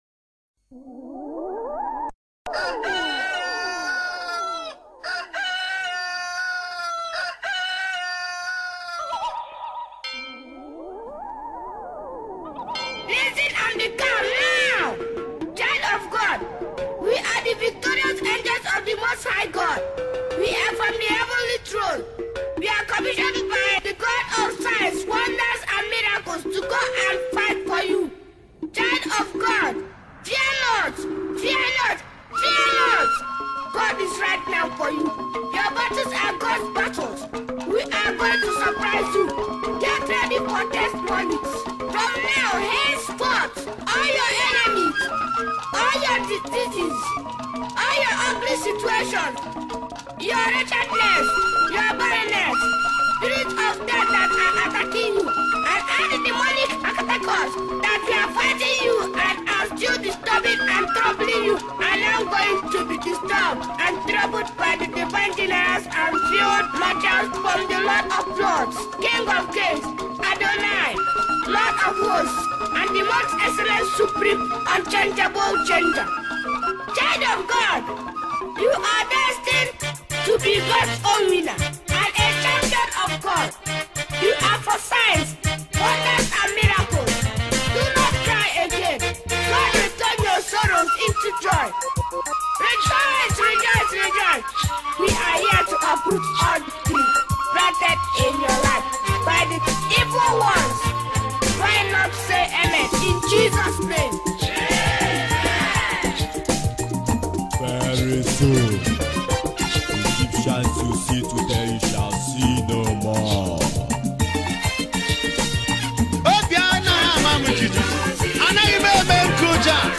February 10, 2025 Publisher 01 Gospel 0